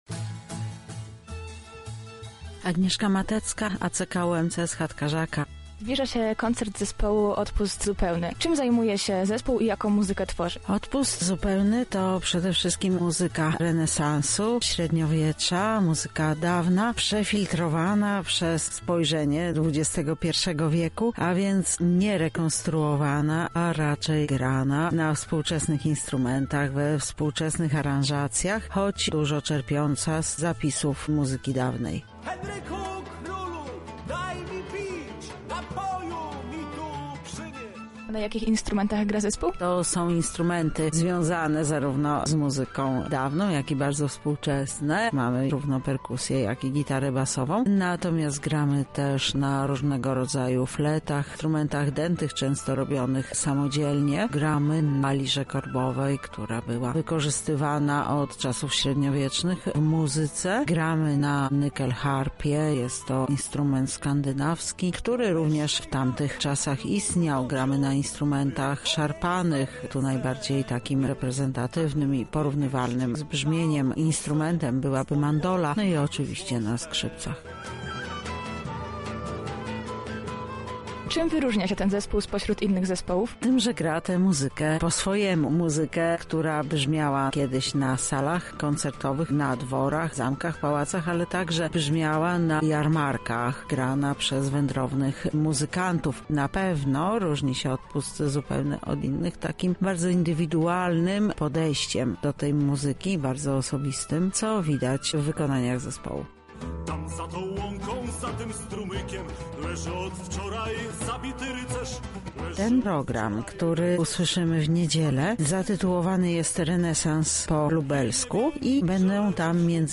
Zapowiedź